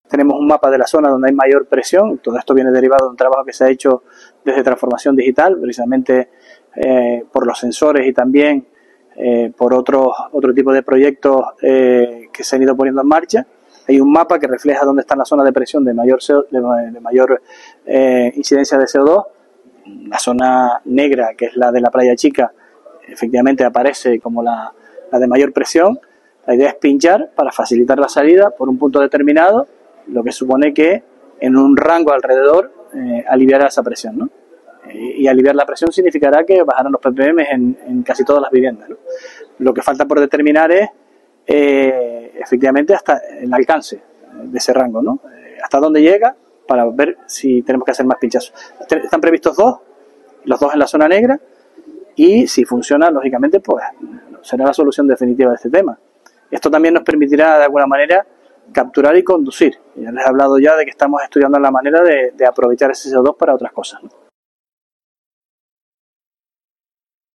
Declaraciones audio Sergio Rodríguez Peinpal (2).mp3